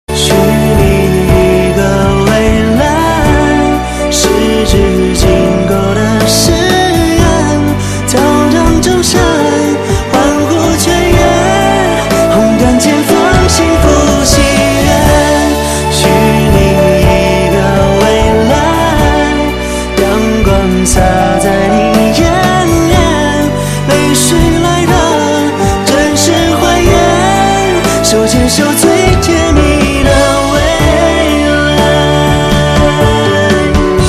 M4R铃声, MP3铃声, 华语歌曲 123 首发日期：2018-05-15 12:05 星期二